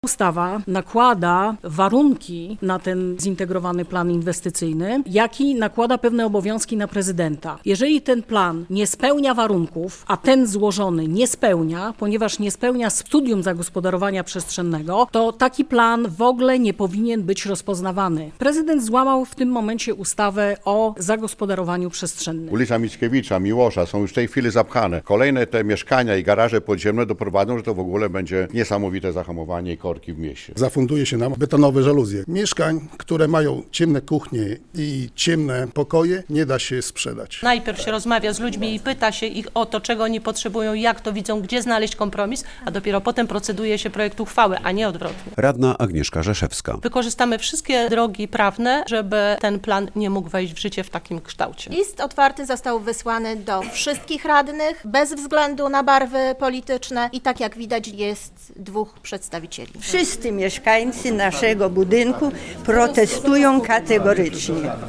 Radio Białystok | Wiadomości | Wiadomości - Mieszkańcy okolic stawów przy ul.